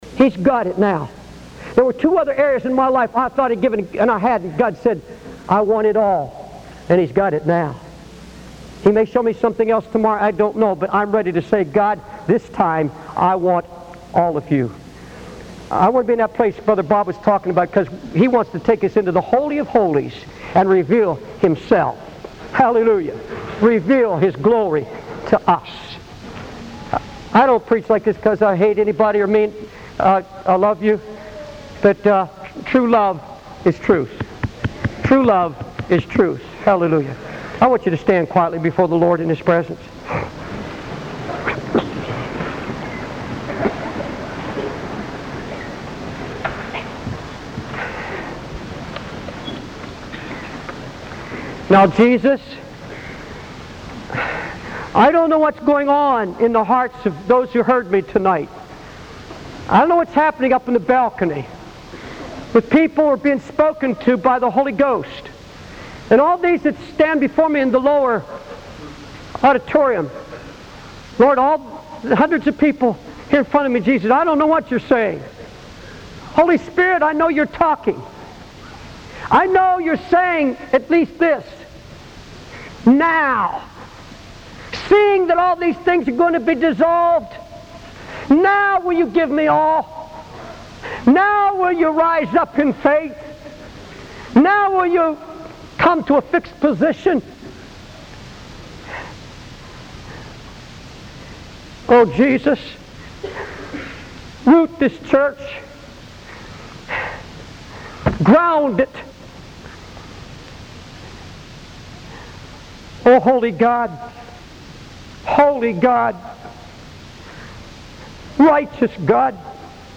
In this sermon, the speaker shares his personal experience of surrendering his life completely to God.